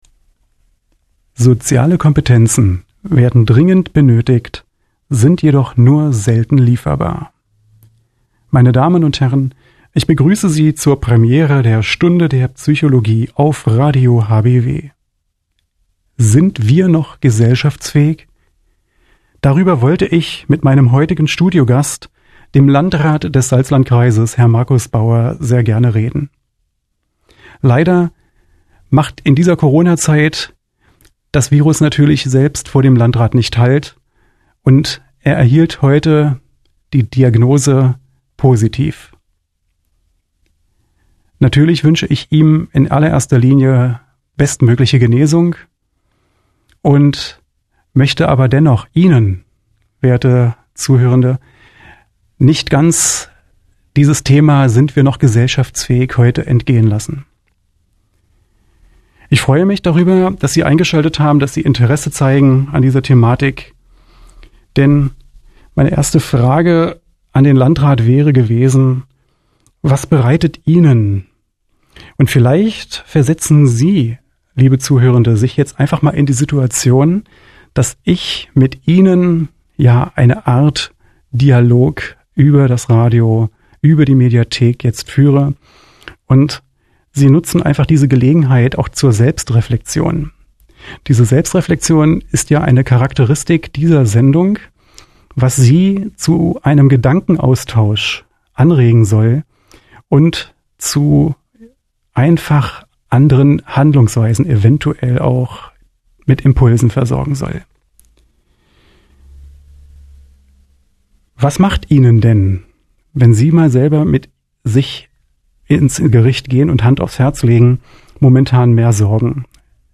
Vorträgen und Gesprächen